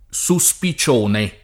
SuSpi©1ne] s. f. — in uso come term. giur.